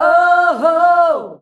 OOOHOO  F.wav